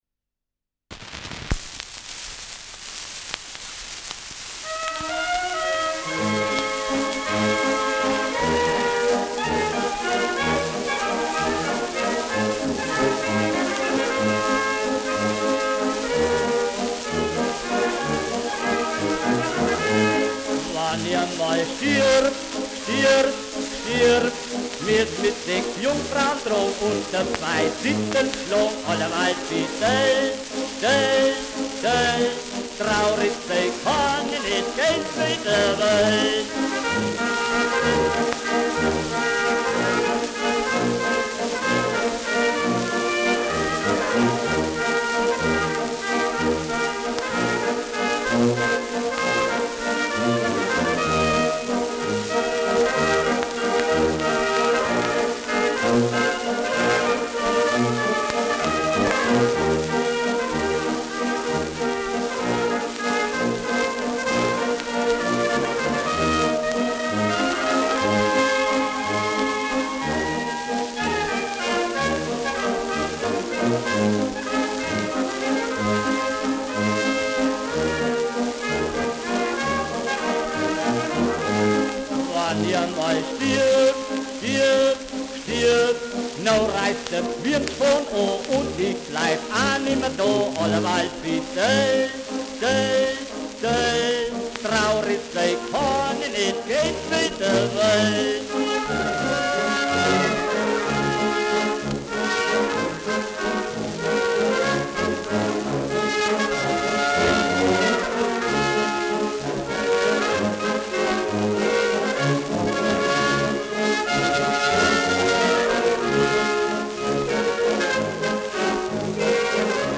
Wann i amal stirb : Walzer : mit Gesang [Wenn ich einmal sterbe : Walzer : mit Gesang]
Schellackplatte
Tonrille: Kratzer Durchgehend Stärker
Starkes Grundrauschen : Gelegentlich leichtes Knacken : Verzerrt an lauten Stellen
[München] (Aufnahmeort)